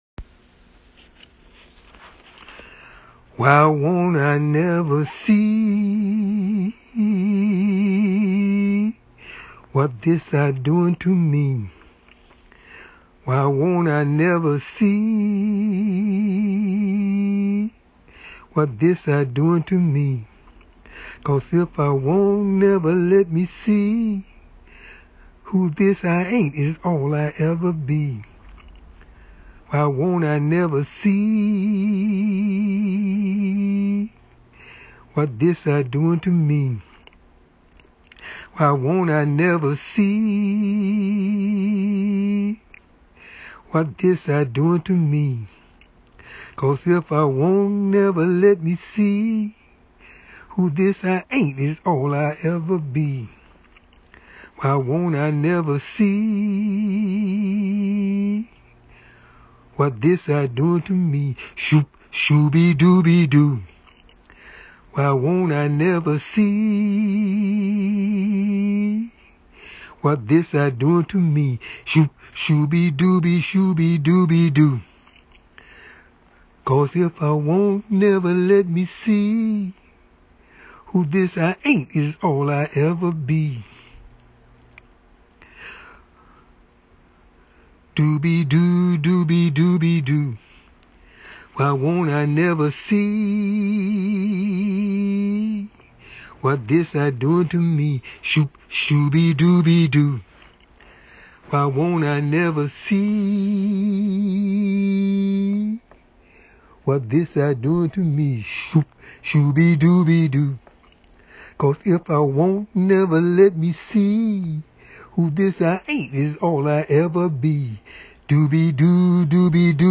(Excerpt from) dark mattering in this spacing of time (a tonal drawing written in poetic form)